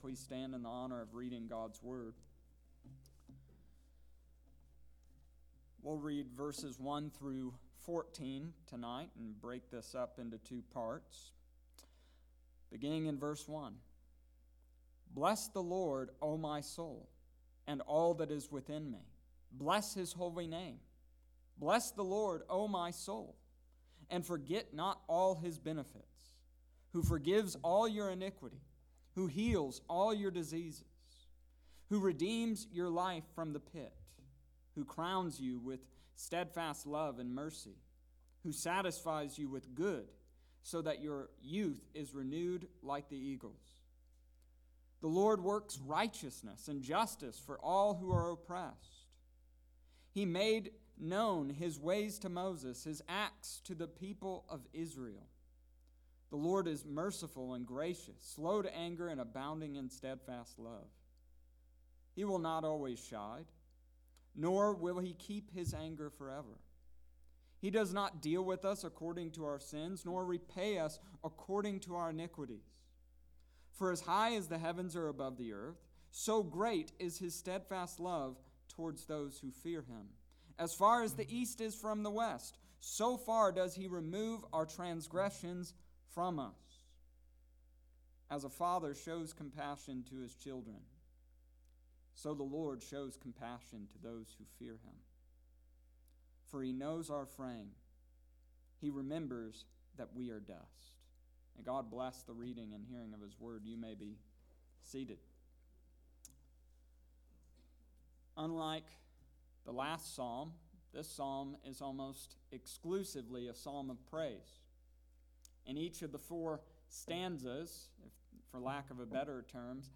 Psalm Chapter 103 verses 1-14 Wed night service May 20-20